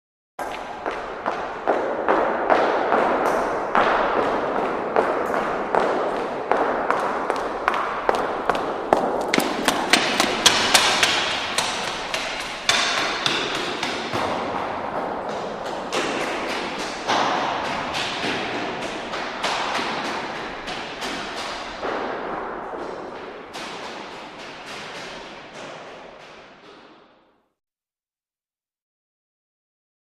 Footsteps By In Echoey Stairwell / Hall 03; Single Person, Hard Heels, Walks Up At Hurried Pace, Passes By Then Up Stone Stairs And Away